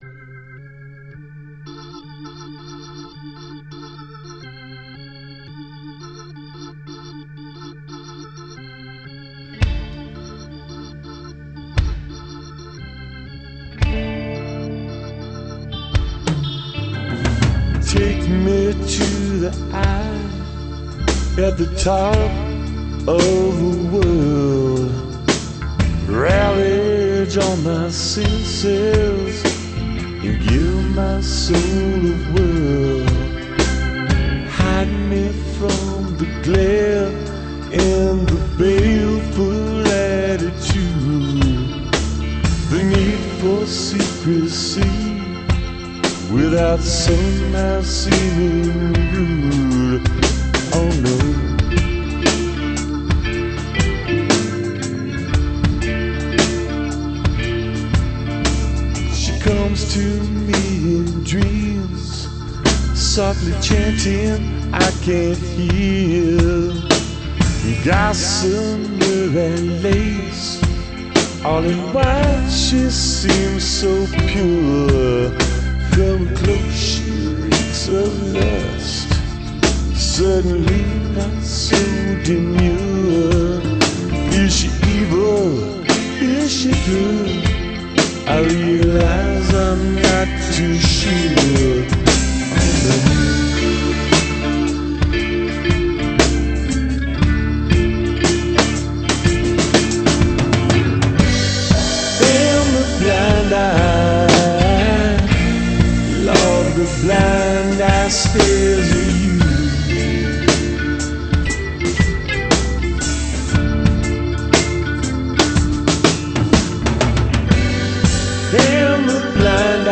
Guitars
Vocals
Bass
Drums & backup vocals
Keyboards